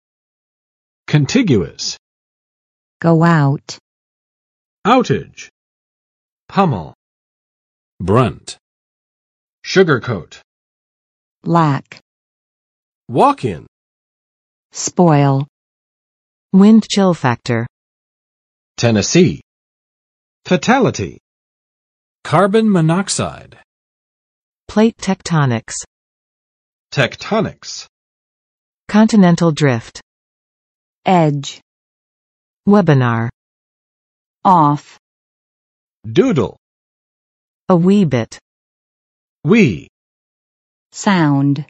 [kənˋtɪgjʊəs] adj. 接触的；邻近的